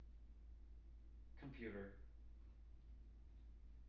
wake-word
tng-computer-396.wav